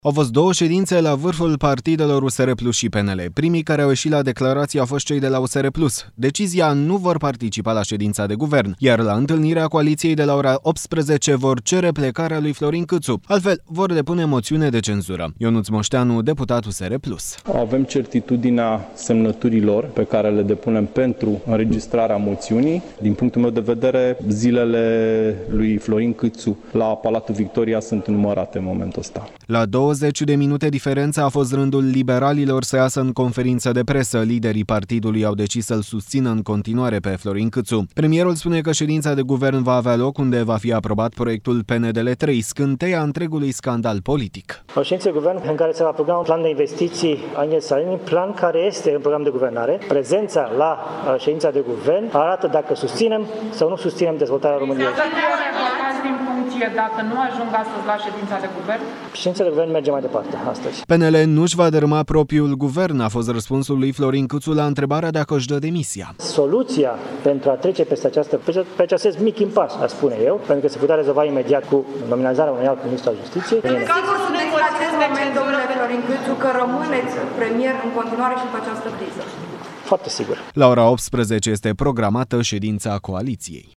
Ionuț Moșteanu, deputat USR Plus:
La două zeci de minute diferență – a fost rândul liberalilor să iasă în conferință de presă.